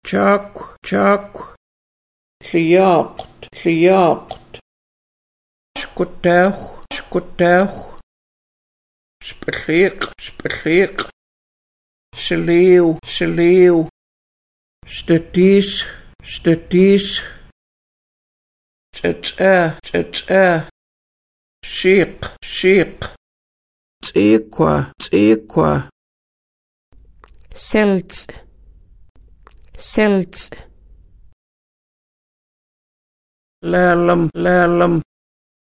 Listen to the elder
Audio Vocabulary and Phrases